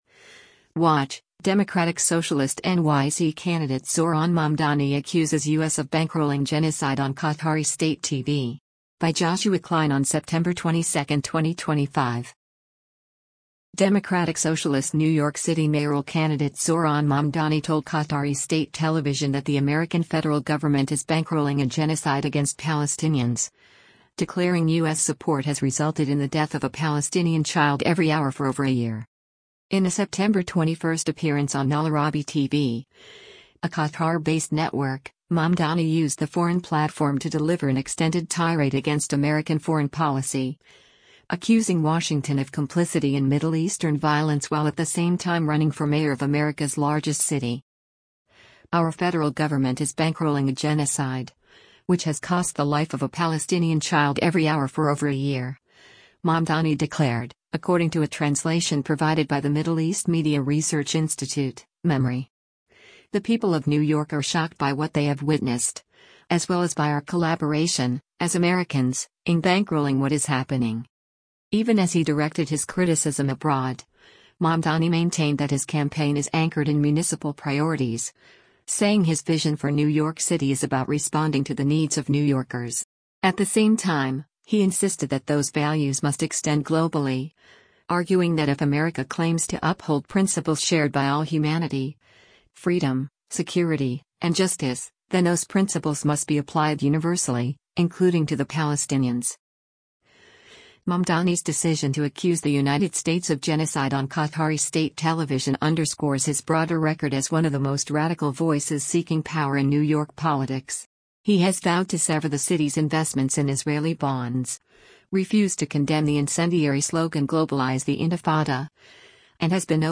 In a September 21 appearance on Al-Araby TV, a Qatar-based network, Mamdani used the foreign platform to deliver an extended tirade against American foreign policy, accusing Washington of complicity in Middle Eastern violence while at the same time running for mayor of America’s largest city.